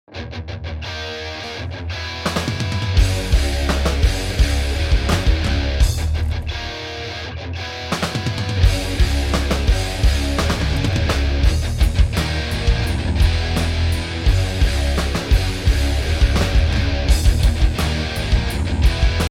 et voici maintenant le même riff joué en enregistré encore deux autres fois (donc trois prises en tout) et placé dans l'espace (gauche centre et droite)
gratte_full.mp3